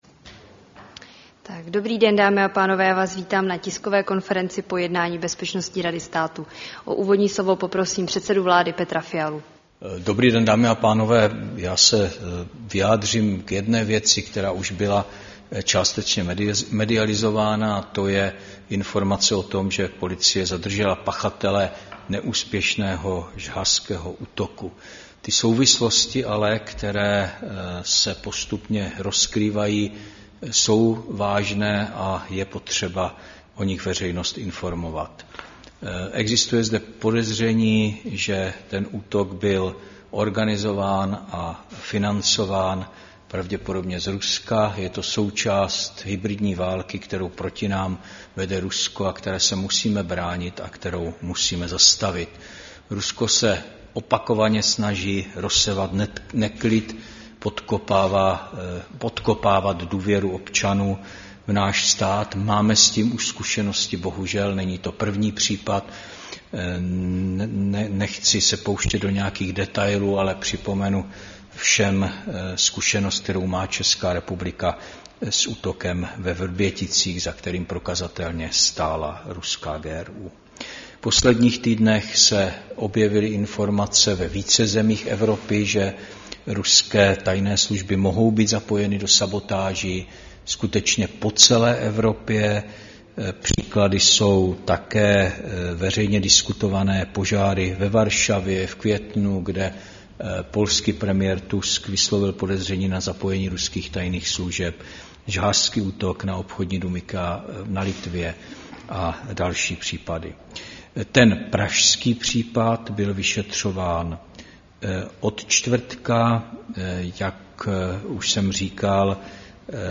Tisková konference po zasedání Bezpečnostní rady státu, 10. června 2024